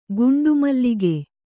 ♪ guṇḍu mallige